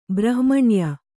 ♪ brahmaṇya